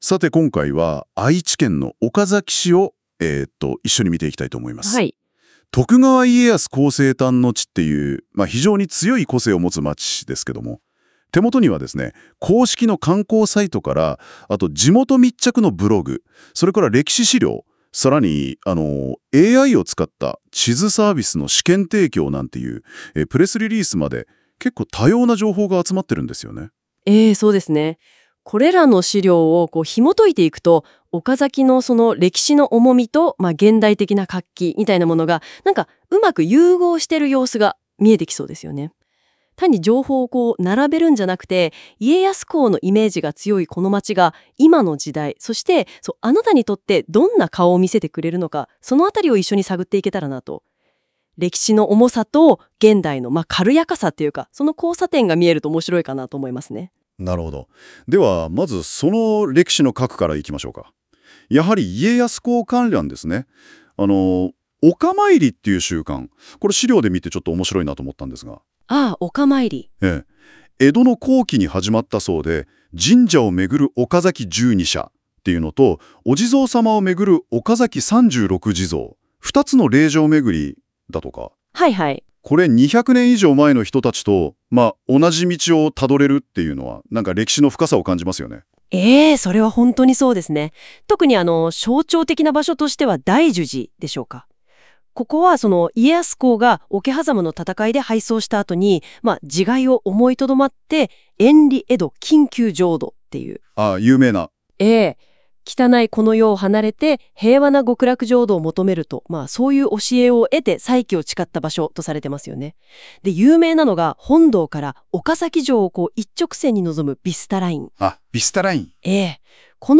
パーソナリティの2人が